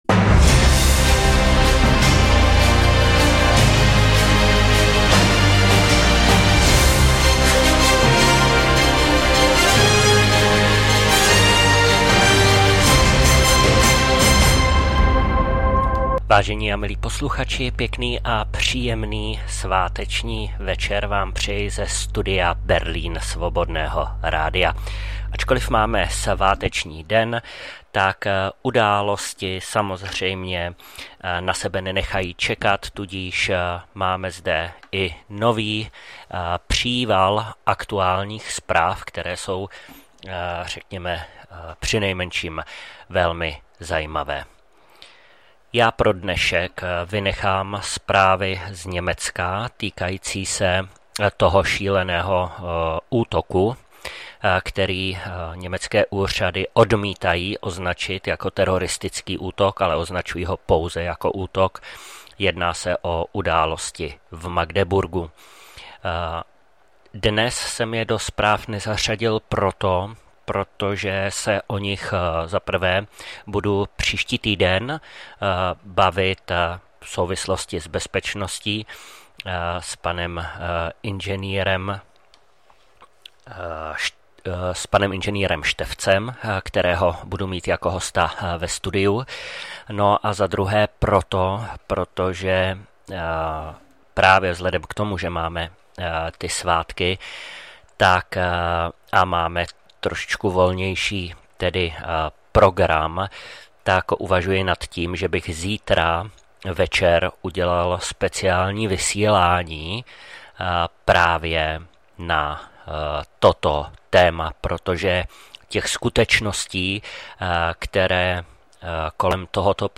2024-12-25 – Studio Berlín – GEOPOLITAN Zpravodajství – Zprávy z domova i ze zahraničí. CNN zaznamenala v roce 2024 nejnižší sledovanost v historii. Jak Bidenova administrativa zakrývala pravdu o prezidentově zdravotním stavu. Čína se v případu dronu v New Jersey vysmívá Americe jako líhni iluzí.